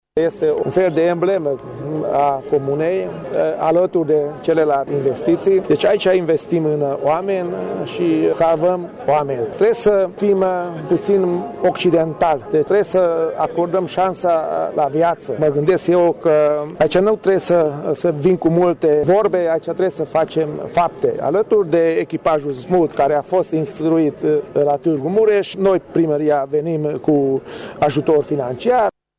Primarul comunei Acăţari, Osvath Csaba, a precizat că, pentru administraţia locală, este foarte important să vină în întâmpinarea nevoii cetăţenilor de a avea servicii medicale de urgenţă: